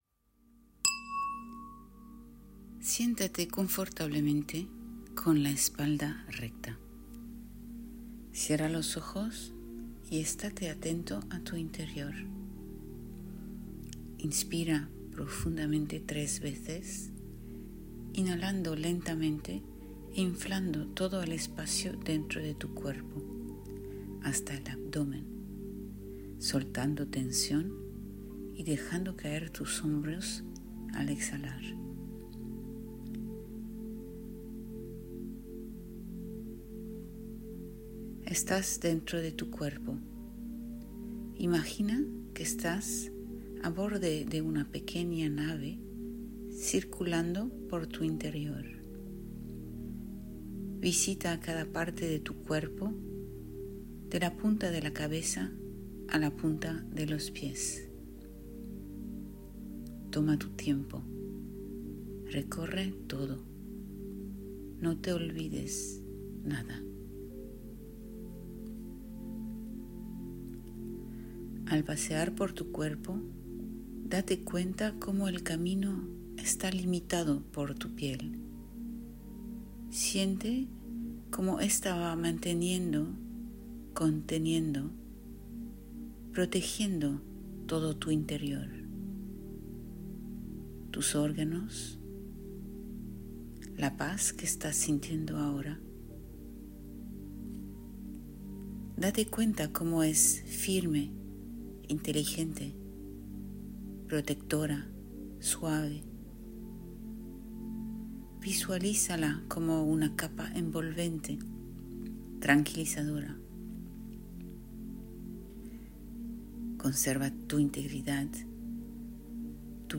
Pequeña meditación de menos de 5 minutos para apropiarte tu cuerpo y ayudarte a respetar tus propias límites.
Meditacion Piel.mp3